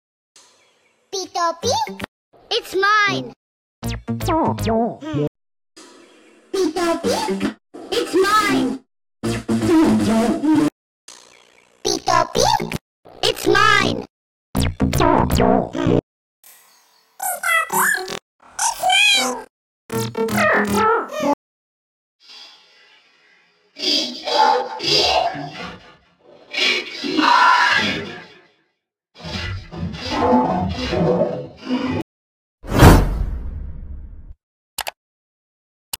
Sound Variations 35sec.